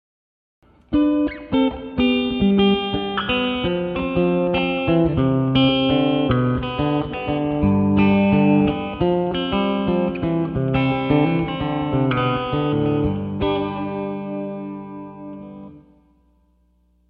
Electric Guitar | Photos and Recordings
496ROC Guitar | Recorded with 30W Transistor Amp (Close-micd 10" Speaker)